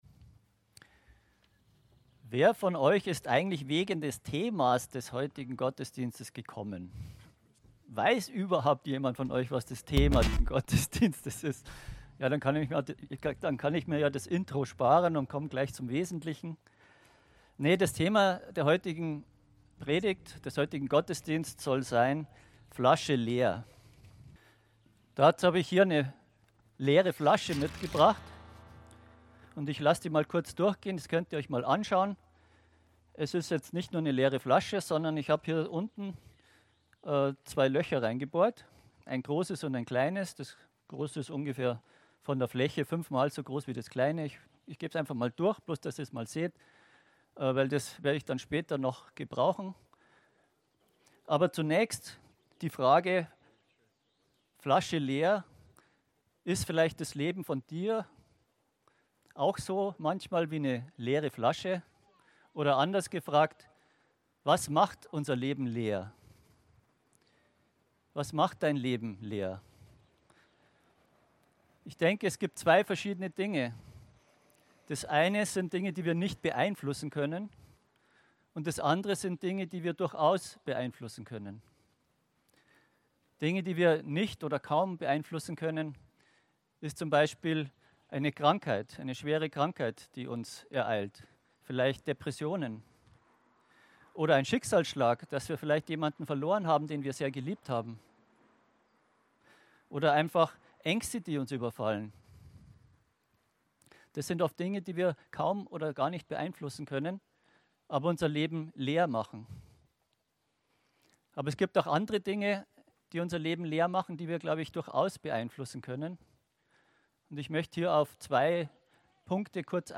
Flasche leer? (Open Air Gottesdienst)